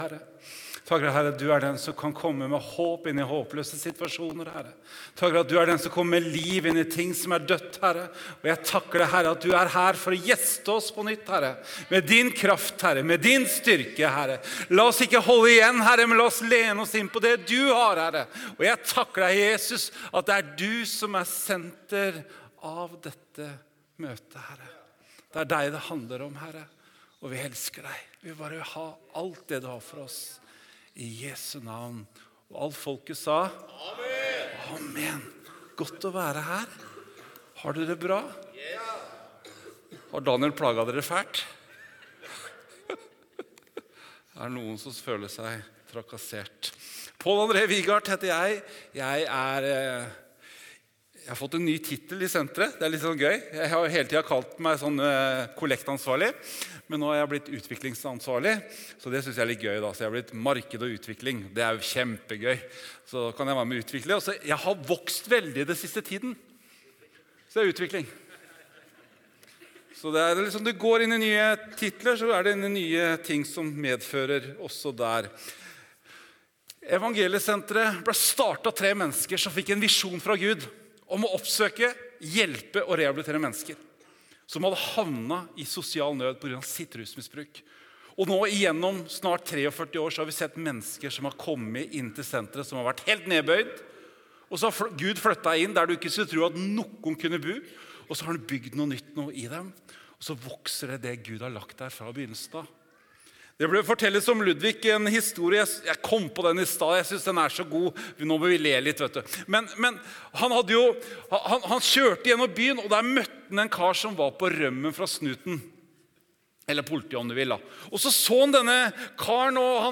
Tale